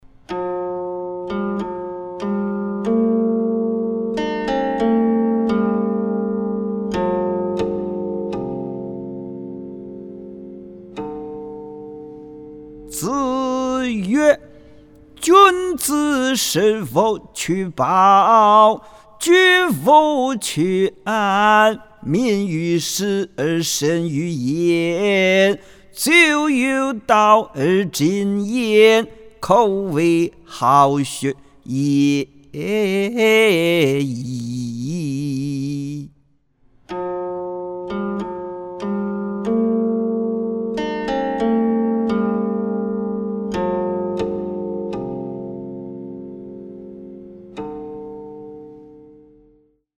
誦唸